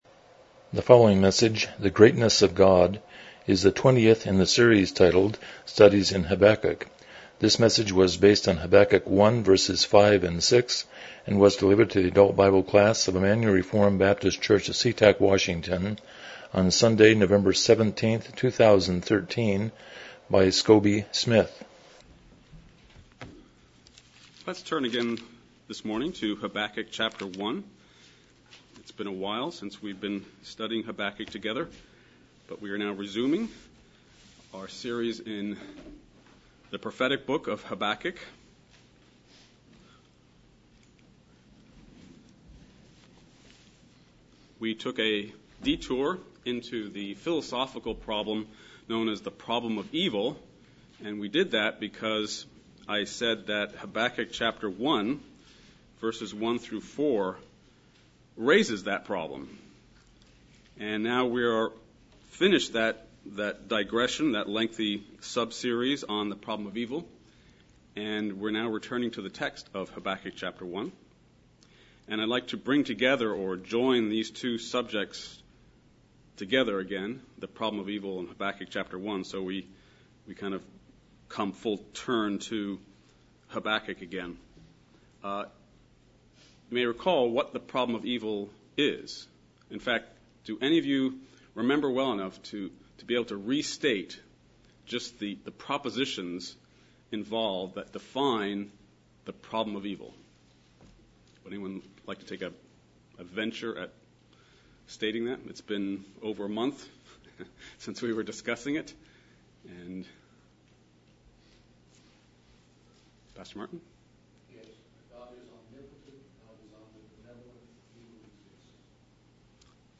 Habakkuk 1:5-6 Service Type: Sunday School « 43 The Sermon on the Mount